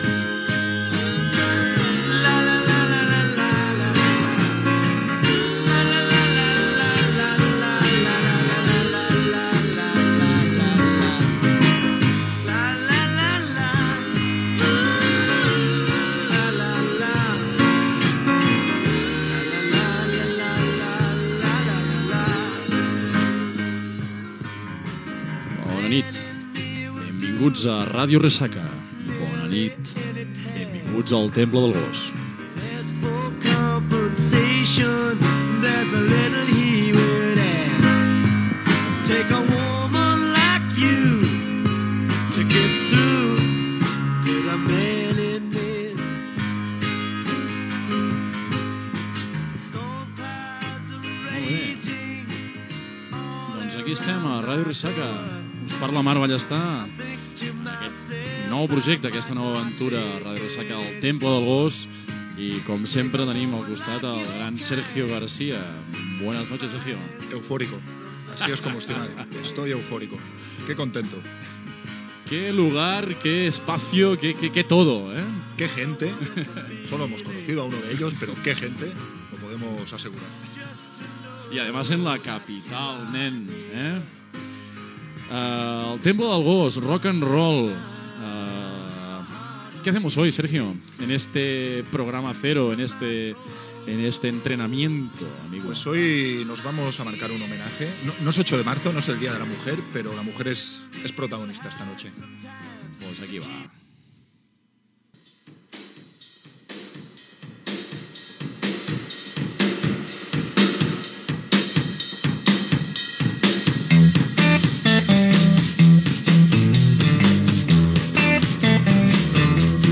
Identificació de l'emissora i del programa, tema musical i estil i continguts del programa.
Musical
FM